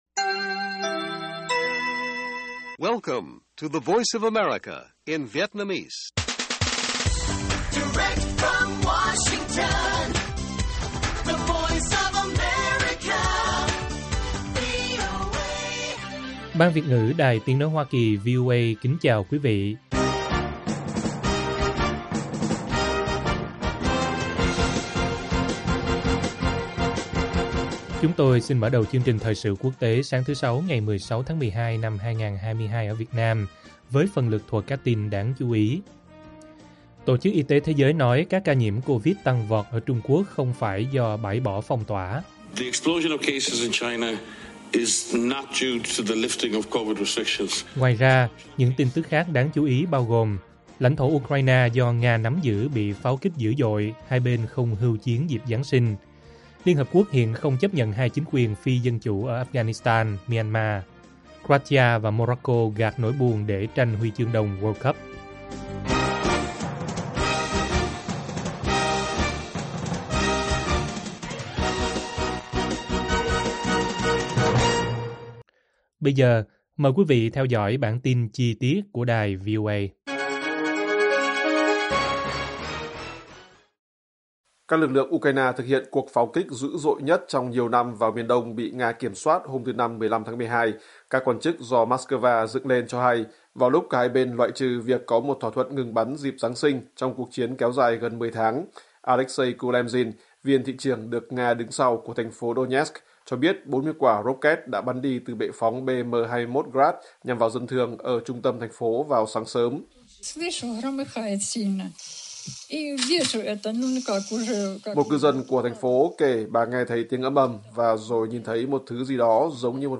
WHO nói COVID tăng ở Trung Quốc không phải do mở phong tỏa - Bản tin VOA